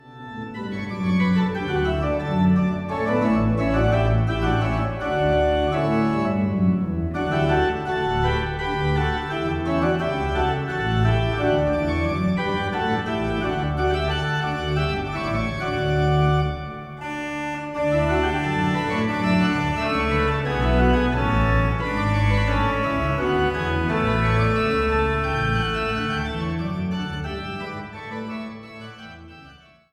Eule-Orgel im Dom zu Zeitz
Orgel